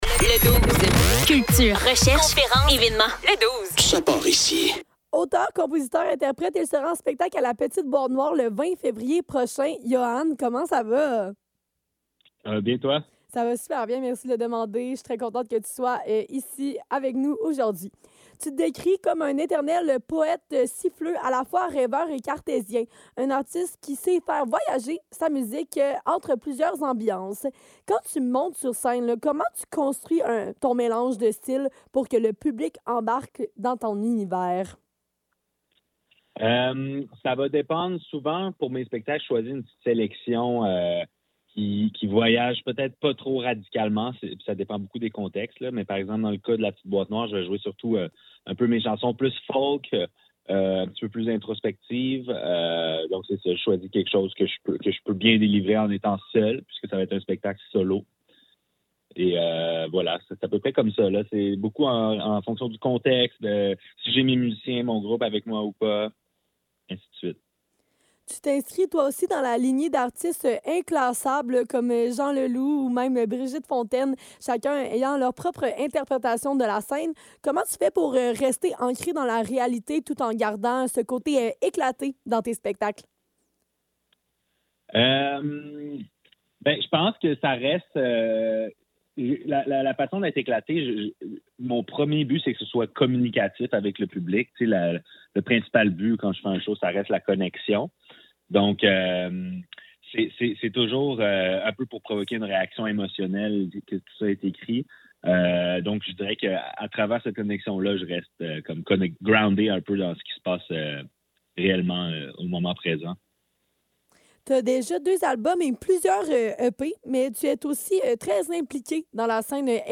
Le Douze - Entrevue